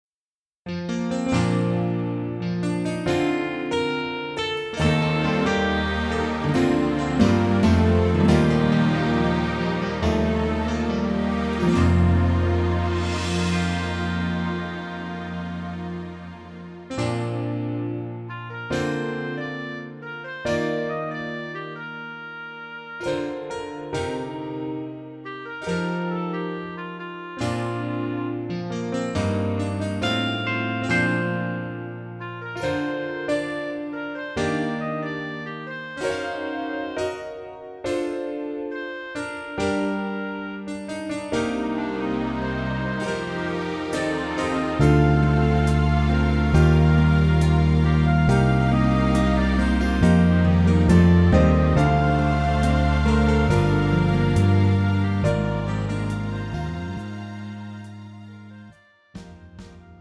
미리듣기
장르 가요 구분 Pro MR